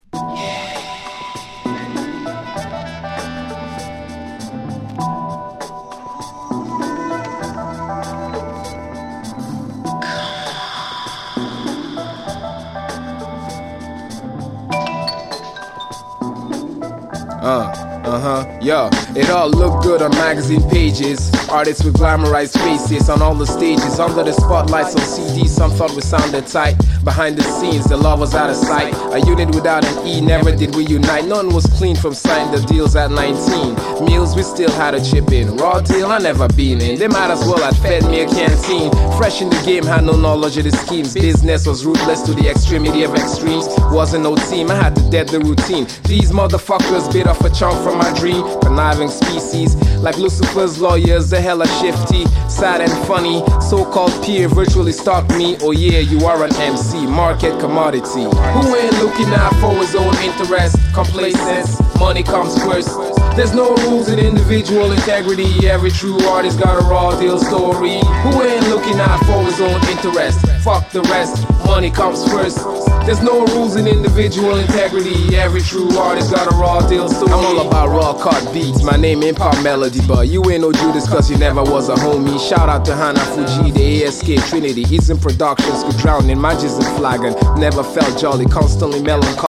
[12”]ブレイクビーツ